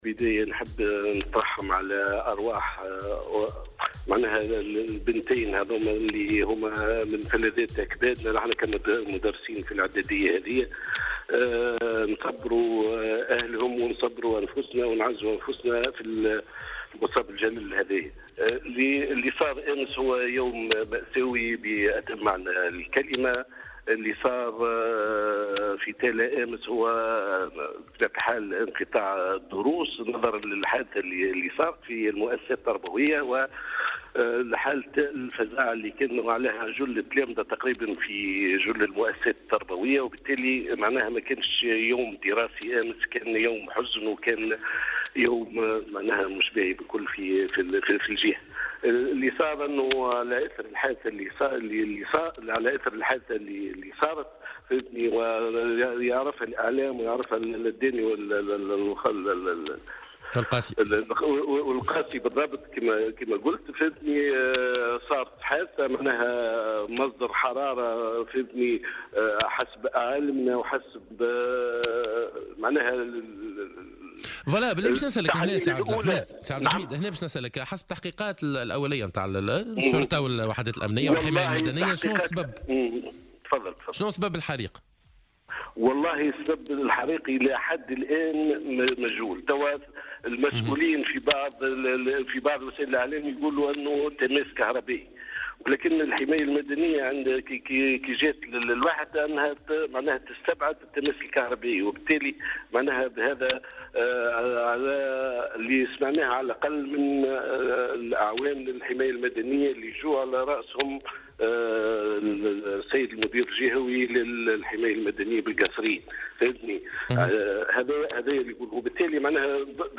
Dans une déclaration accordée à Jawhara FM